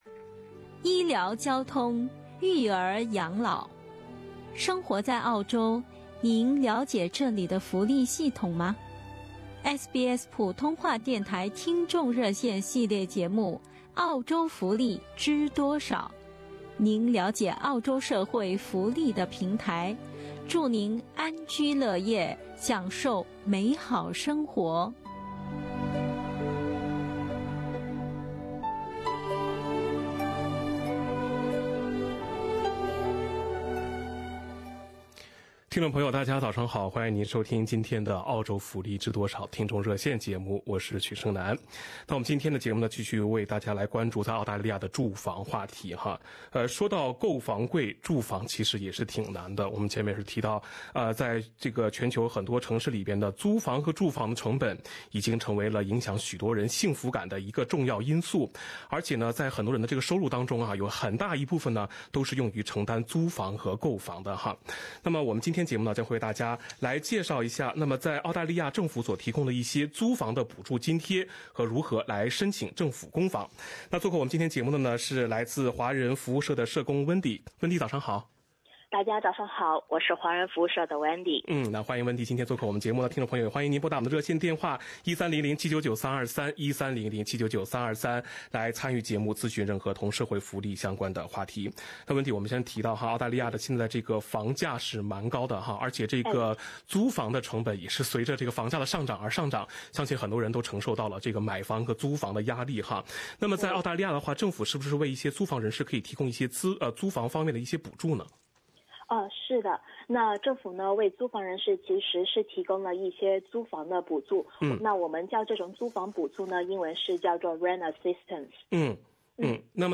本期《澳洲福利知多少》听众热线节目